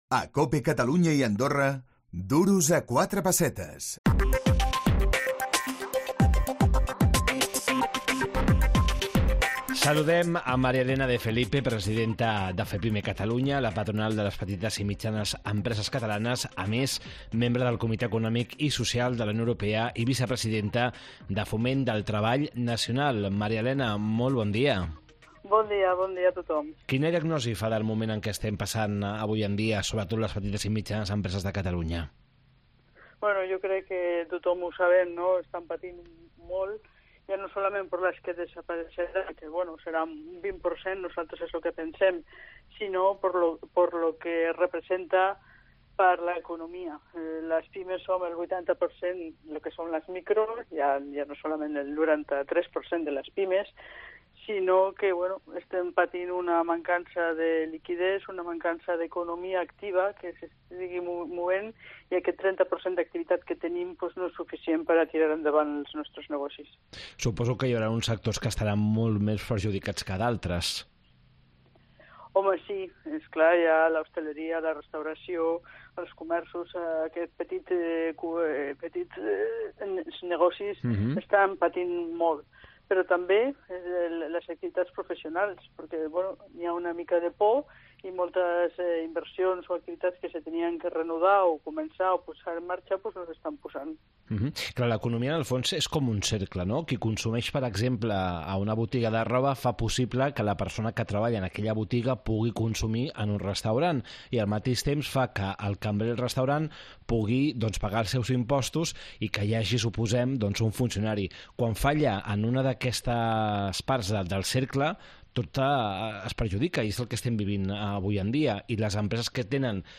Entrevista
Duros a quatre pessetes, el programa d’economia de COPE Catalunya i Andorra.